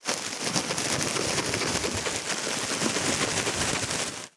653スーパーの袋,袋,買い出しの音,ゴミ出しの音,袋を運ぶ音,
効果音